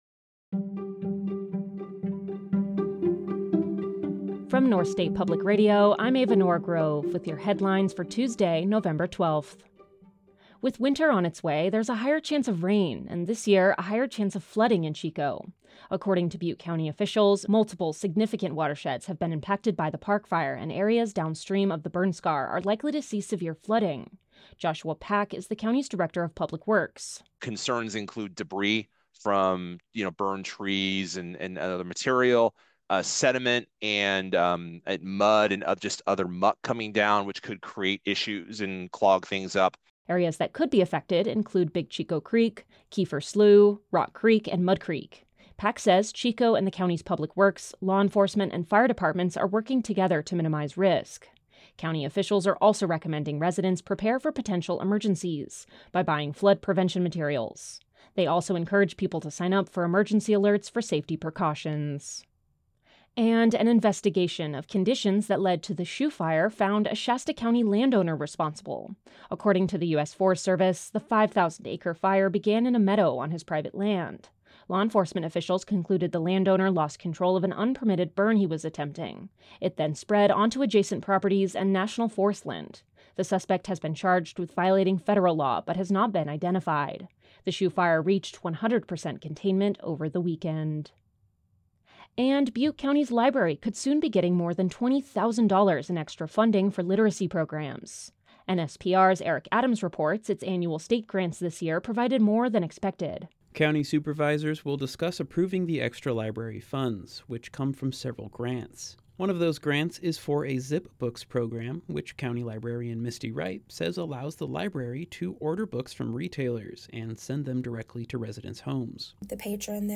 Six years after the Camp Fire: A conversation